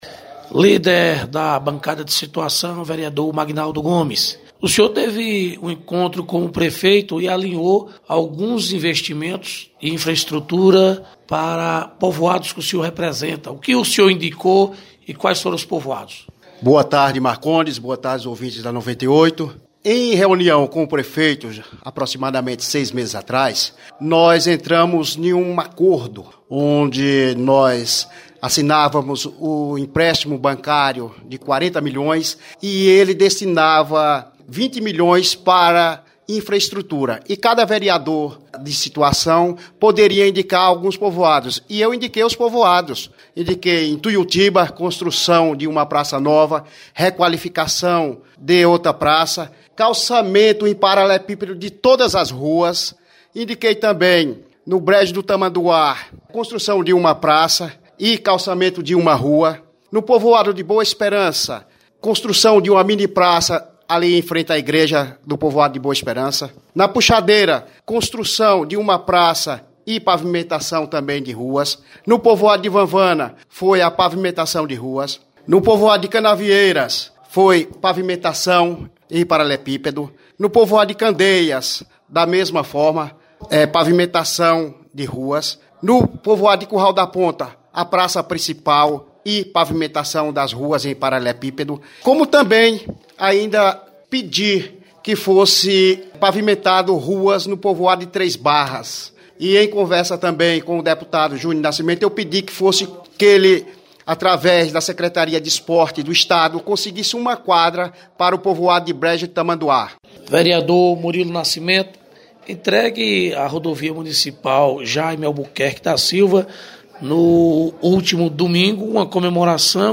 Reportagem com os Vereadores de CFormoso na abertura dos trabalhos legislativos após recesso parlamentar